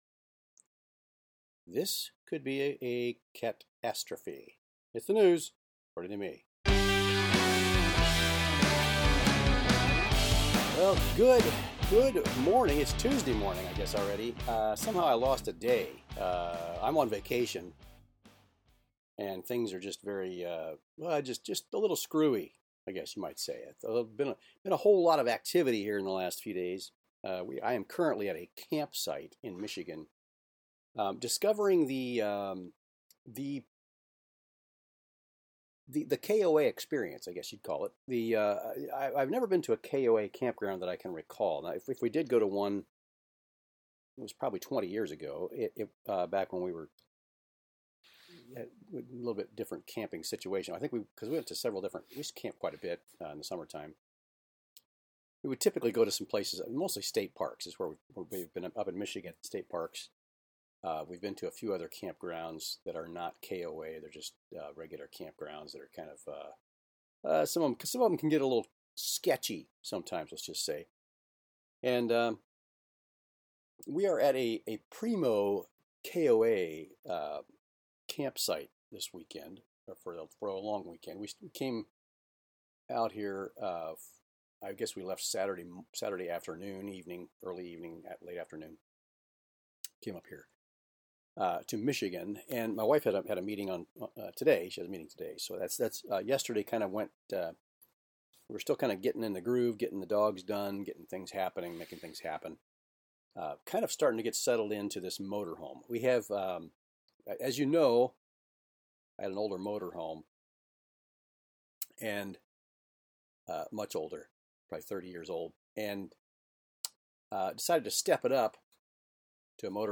Out camping this week so the show is a little different.